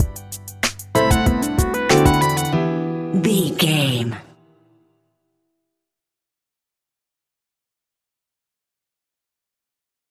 Upbeat Urban Stinger.
Aeolian/Minor
chilled
laid back
hip hop drums
hip hop synths
piano
hip hop pads